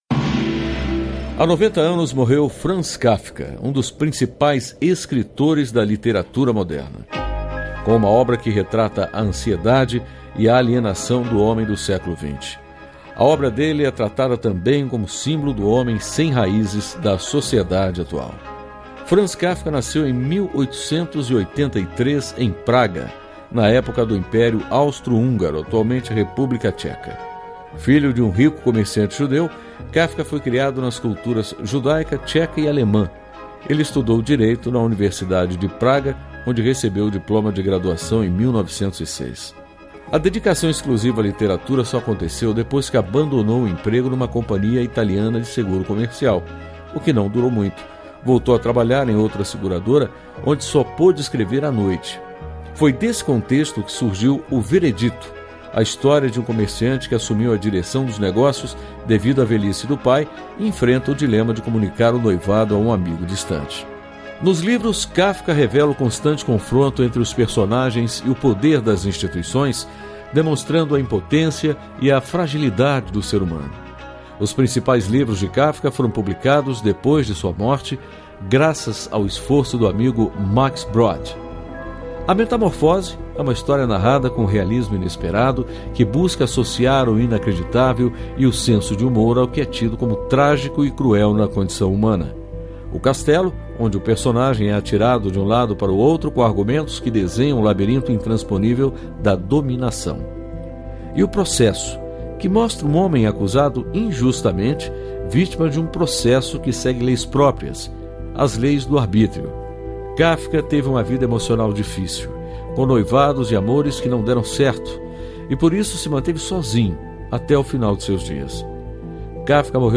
História Hoje : Programete sobre fatos históricos relacionados às datas do calendário.